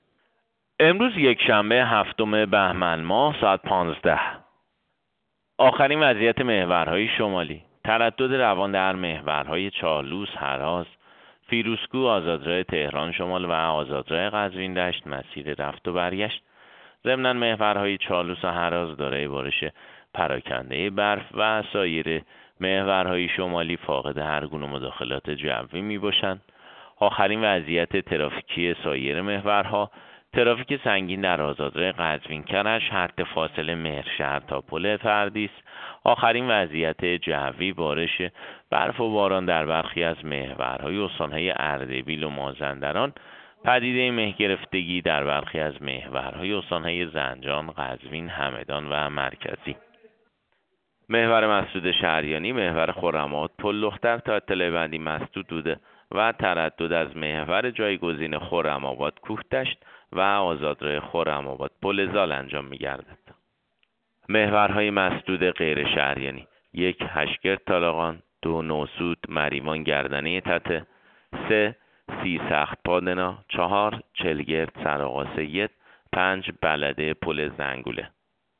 گزارش رادیو اینترنتی از آخرین وضعیت ترافیکی جاده‌ها ساعت ۱۵ هفتم بهمن؛